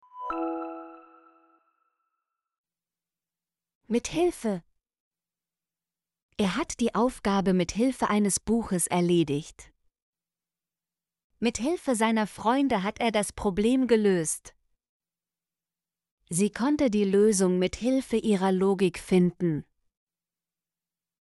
mithilfe - Example Sentences & Pronunciation, German Frequency List